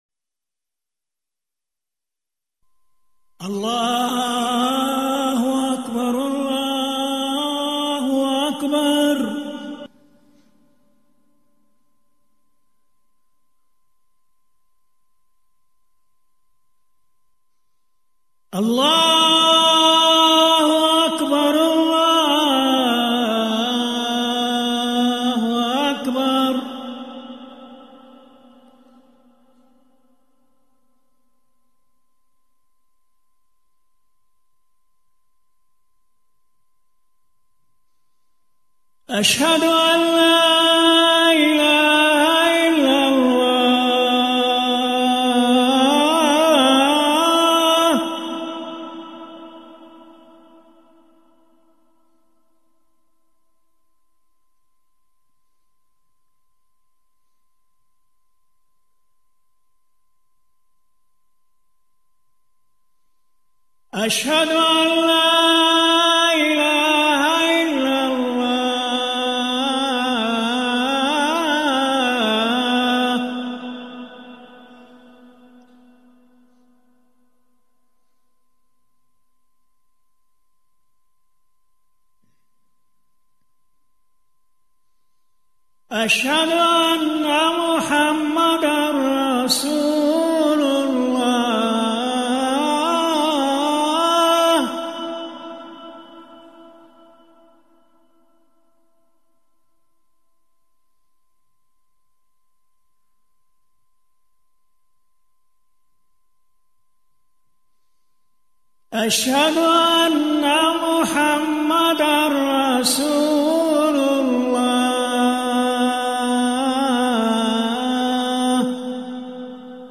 أذان
المكان: المسجد النبوي الشيخ